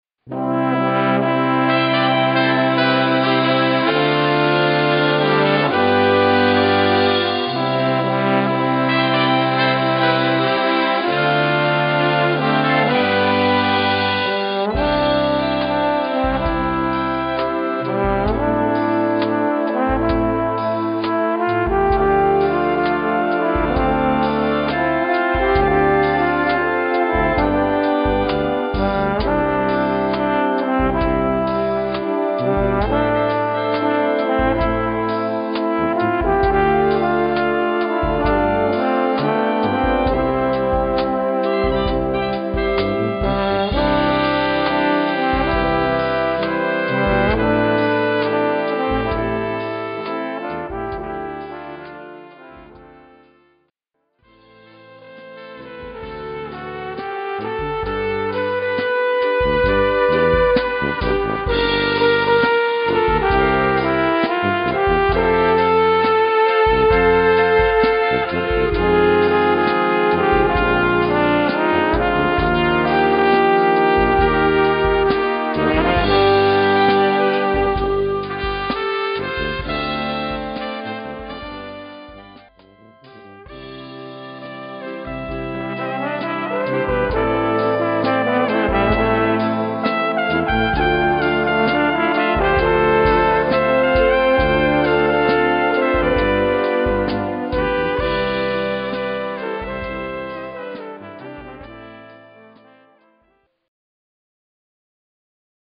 Gattung: Solo für Posaune und Blasorchester
Besetzung: Blasorchester
Solo: Stufe 4